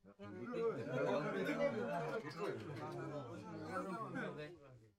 Crowd Walla
Indistinct crowd conversation murmur with natural speech rhythms and varied vocal tones
crowd-walla.mp3